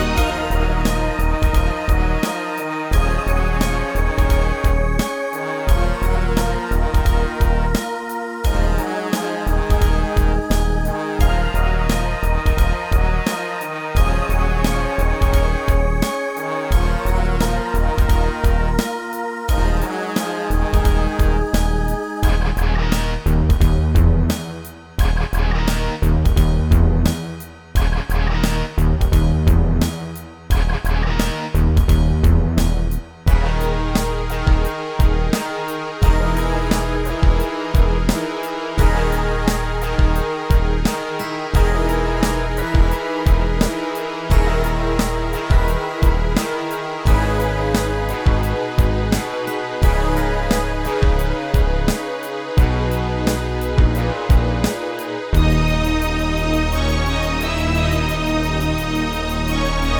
versione strumentale multitraccia